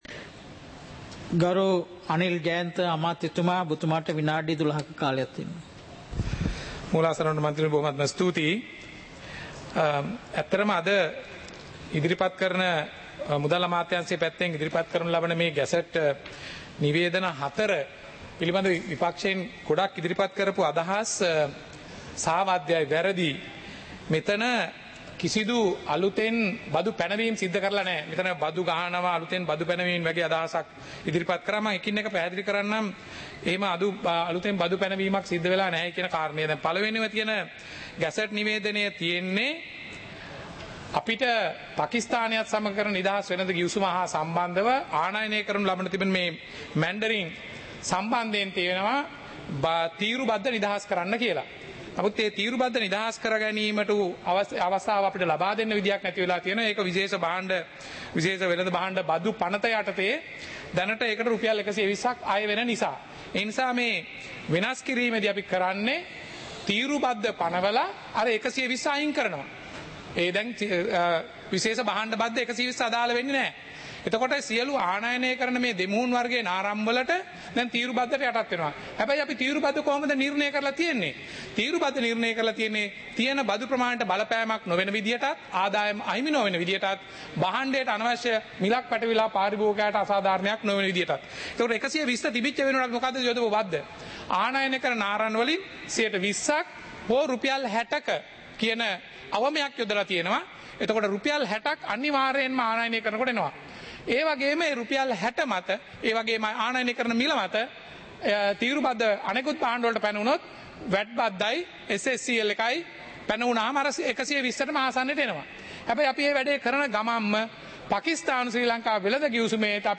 சபை நடவடிக்கைமுறை (2026-02-18)
நேரலை - பதிவுருத்தப்பட்ட